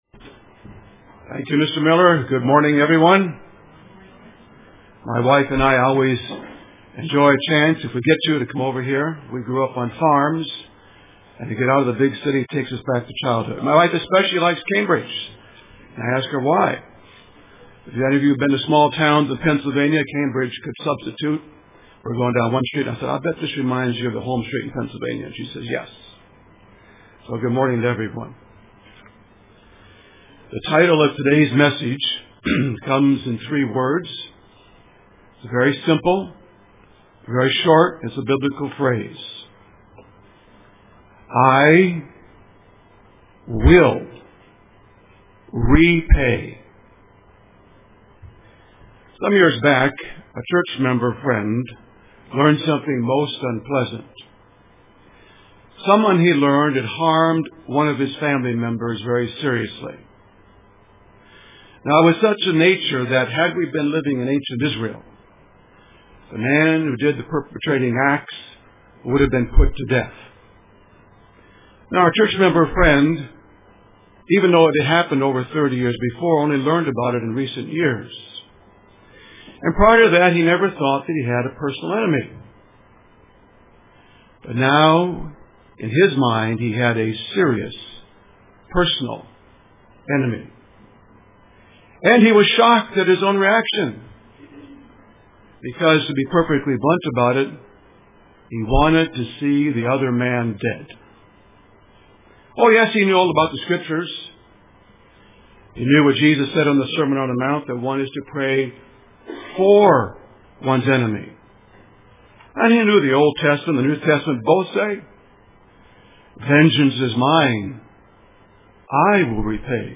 God said He will repay UCG Sermon Studying the bible?